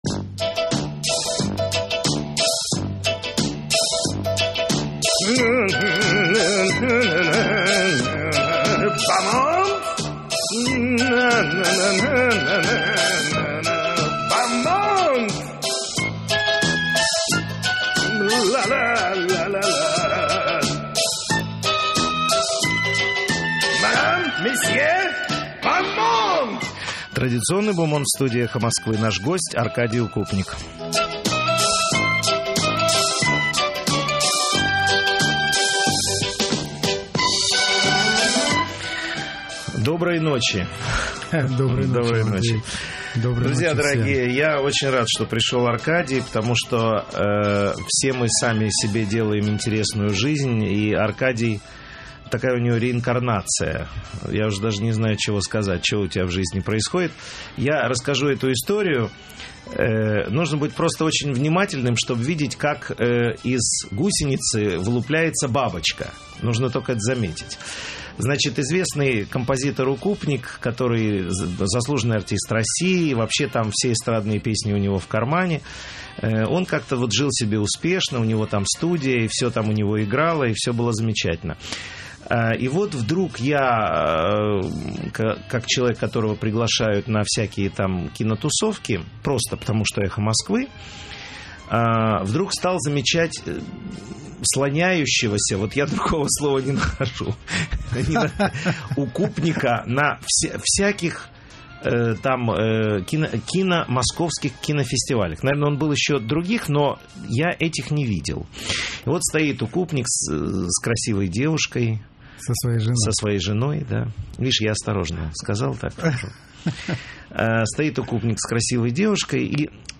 В эфире радиостанции «Эхо Москвы» - Аркадий Укупник, композитор.